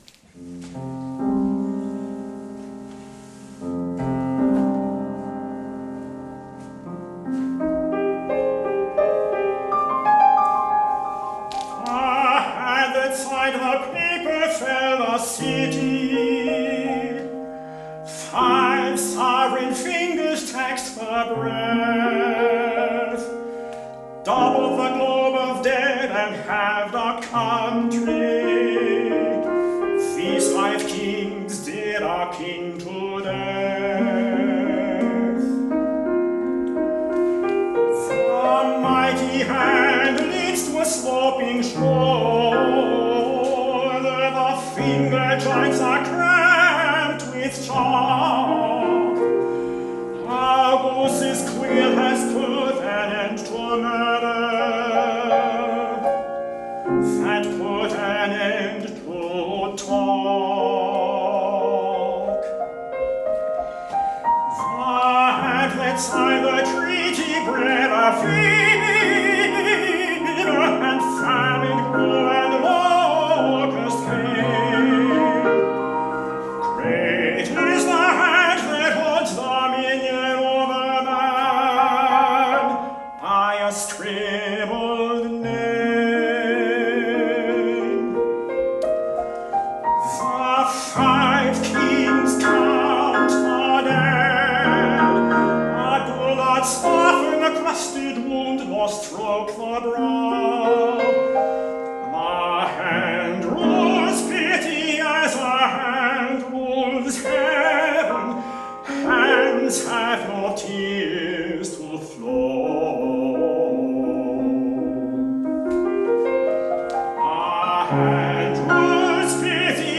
for Tenor and Piano (2011)
Tempi are slow, and minor keys are prevalent.